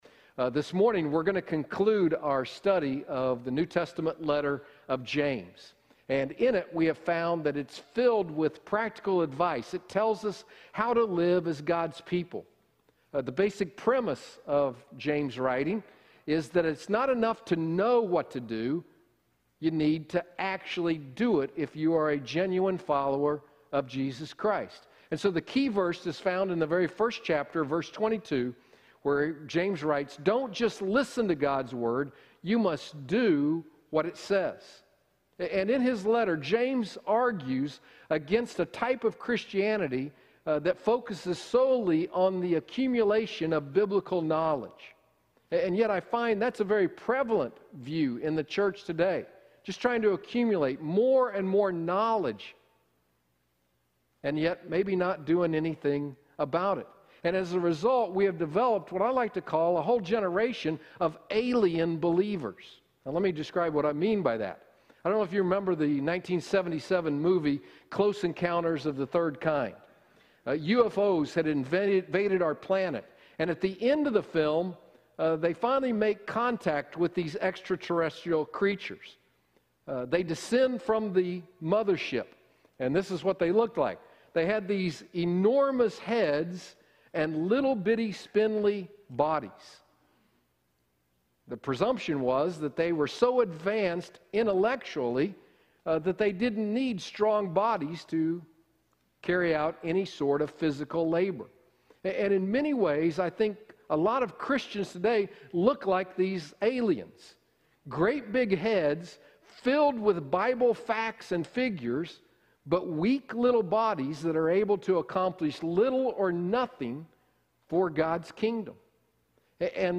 Watch on YouTube Listen to sermon mp3 Do you remember the 1977 movie, Close Encounters of the Third Kind ?